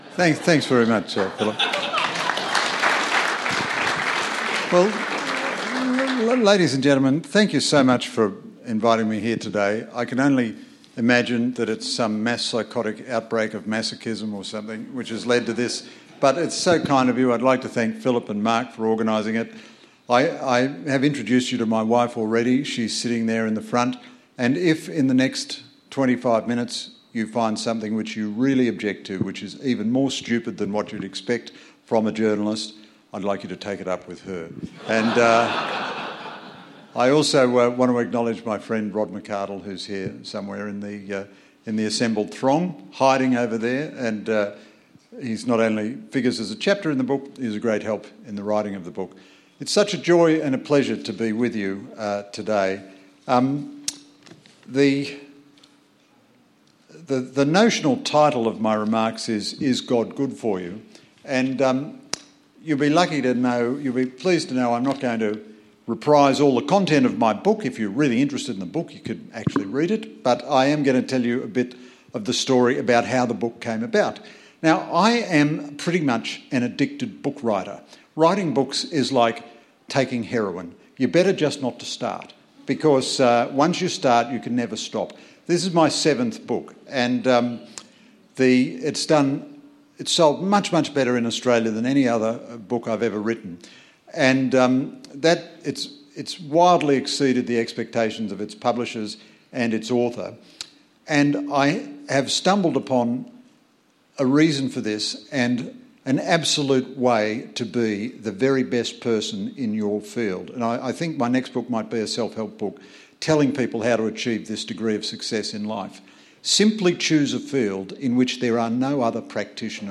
In this sermon, Greg Sheridan speaks on the theme of 'Men's & Women's Grill with Greg Sheridan - Is God Good for You?' as part of the series 'Men's & Women's Grill'.